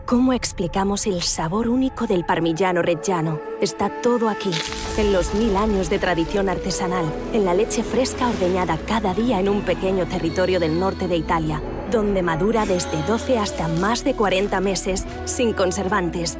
Adult (30-50) | Yng Adult (18-29)